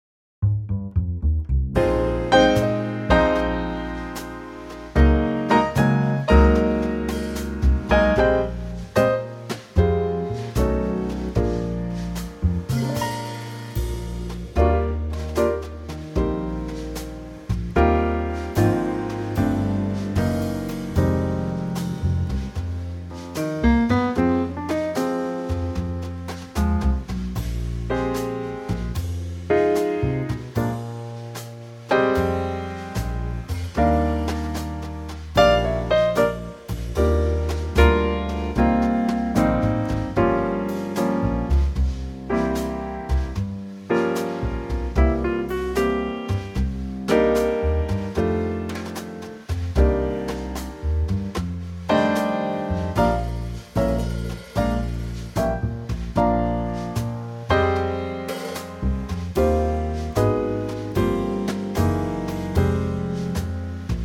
Unique Backing Tracks
key Eb 3:59
key - Eb - vocal range - F# to Ab
Superb Trio arrangement of the classic standard